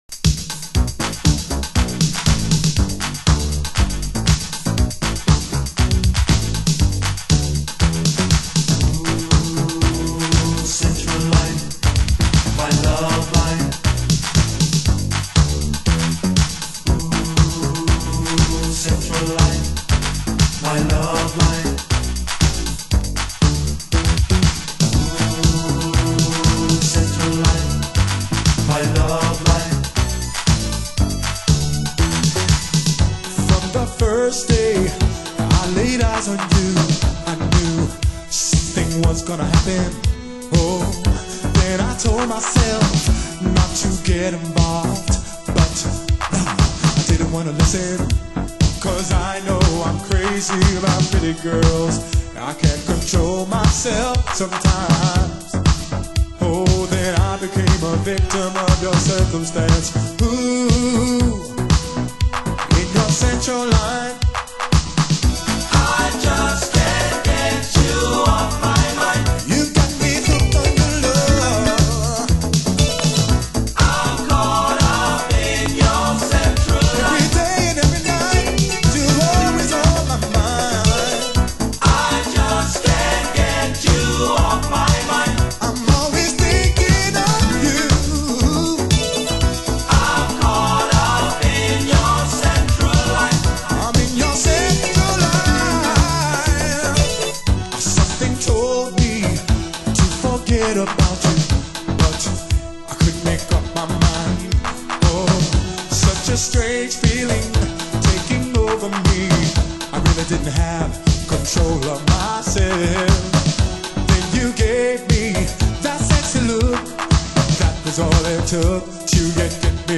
盤質：A面前半部に傷ノイズ 有/少しチリパチノイズ有